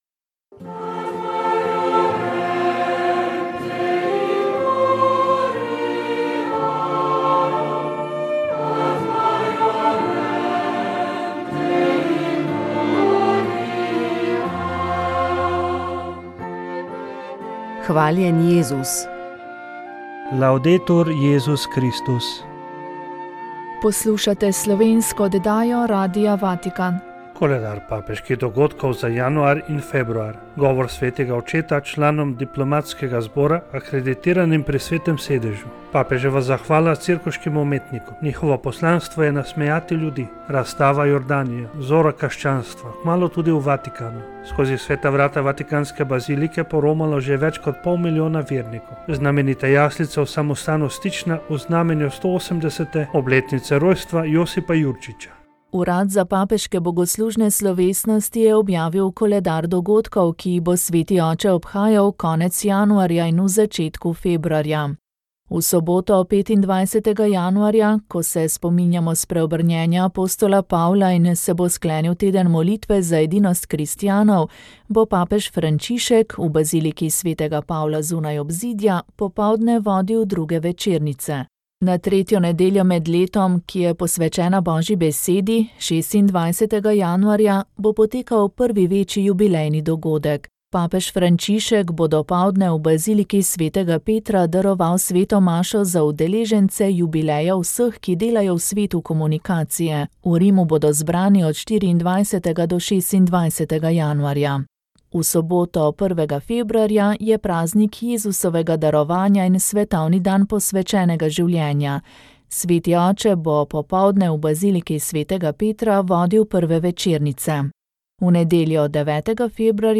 Ob Dnevu samostojnosti smo gostili Marka Lotriča, predsednika Državnega sveta. Kaj lahko še storimo za dvig narodne identitete in pripadnosti, kako izkušnje iz gospodarskega, poslovnega sveta vpeljuje v politično delovanje, kakšna je klima v vrhu državnega ustroja naše domovine in tudi, kako spodbujati sodelovanje ter spretnost vodenja med različnimi interesi za čim večjo dobrobit družbe in ljudi?, so bila izhodišča pogovora.
svetovanje družba pogovor politika